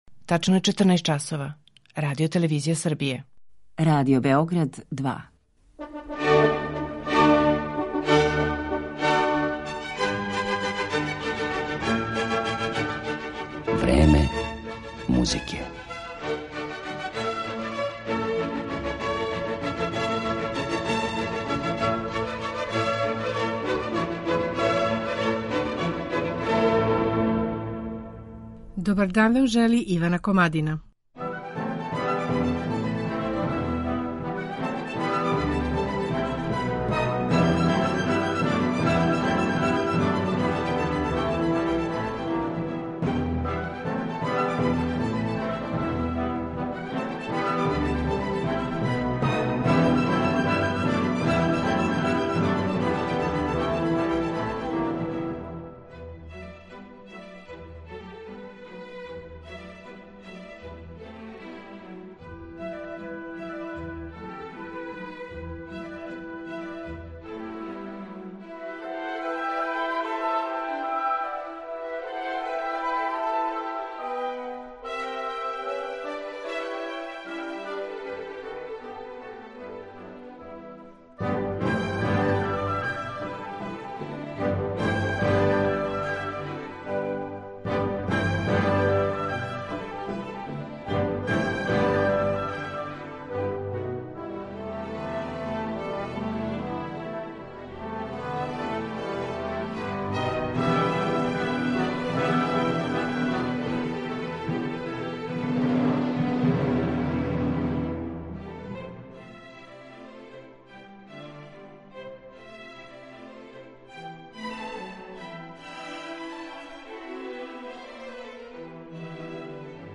У данашњем Времену музике представићемо ауторски лик Флоренс Прајс њеним оркестарским и клавирским делима.